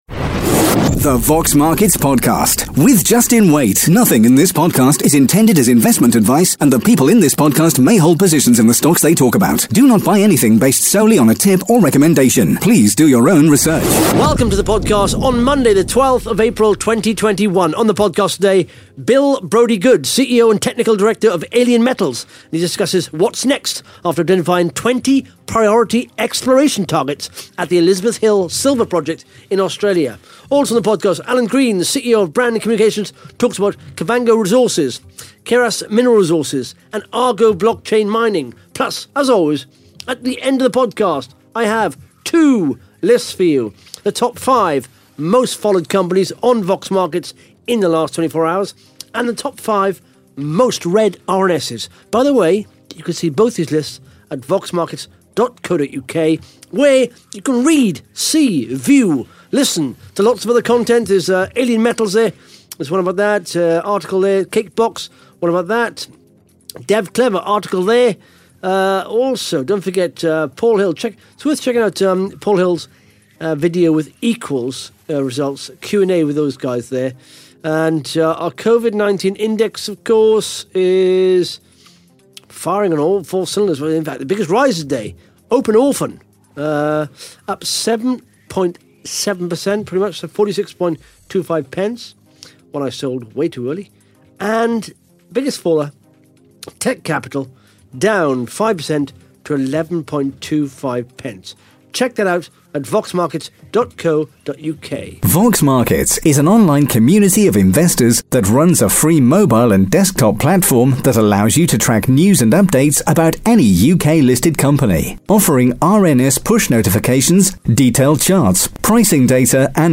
(Interview starts 13 minutes 56 seconds)